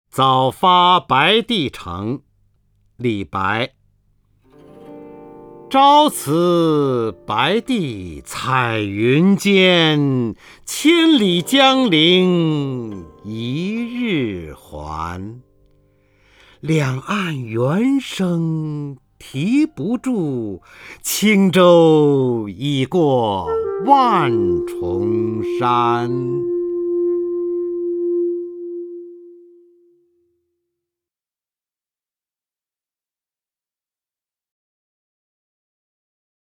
方明朗诵：《早发白帝城》(（唐）李白) （唐）李白 名家朗诵欣赏方明 语文PLUS